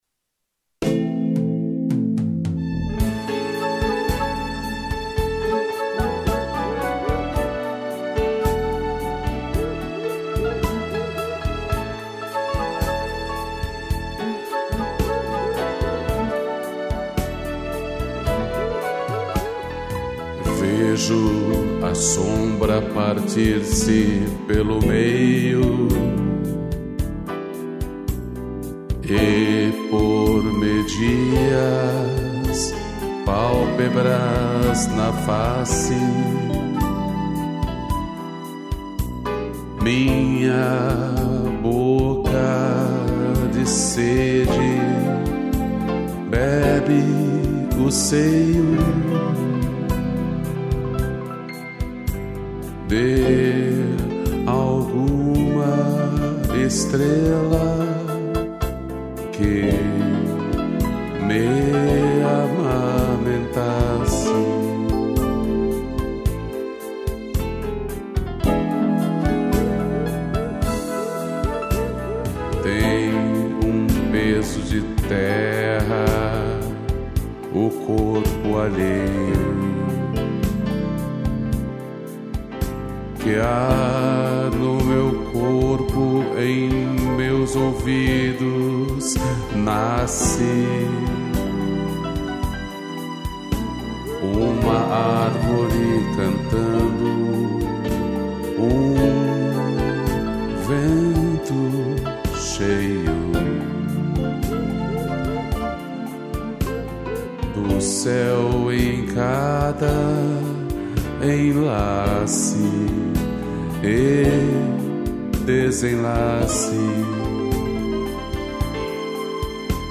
piano, strings, flauta e cuíca